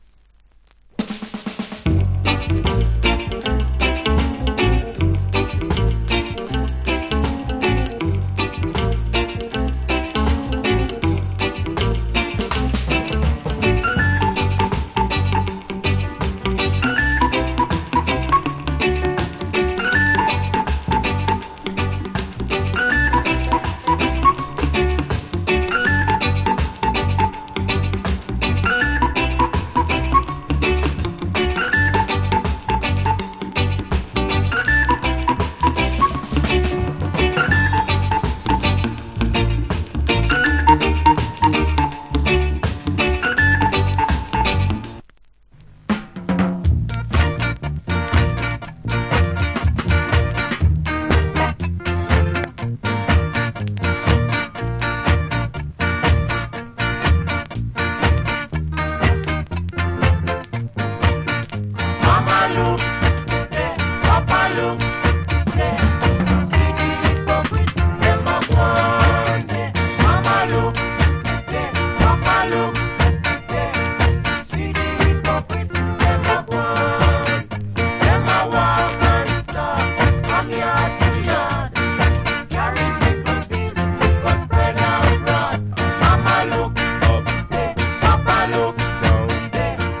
Wicked Early Reggae Inst.